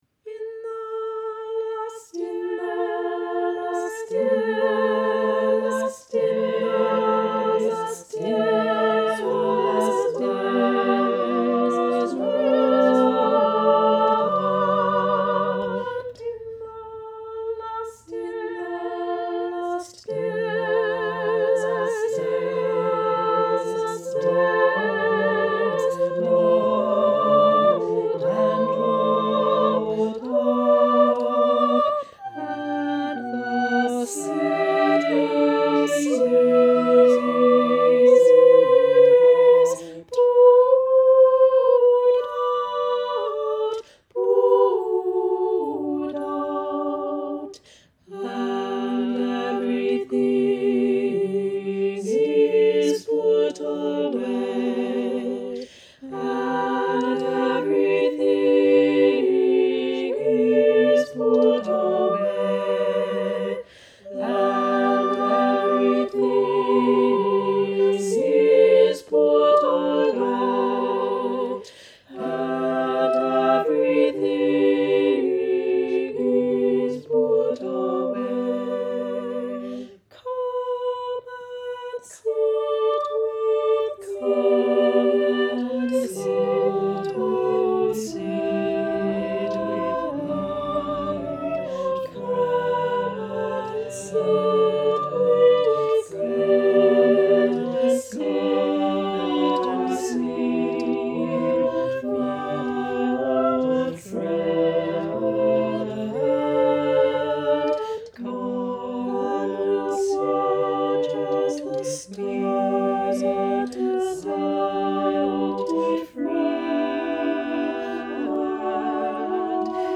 In the Last Days - Choral, Vocal
I wrote this piece ten years ago, but just went through and made an mp3 with myself singing all the parts. (Pardon my bass line, and thank you autotune for allowing me to fake a bass part).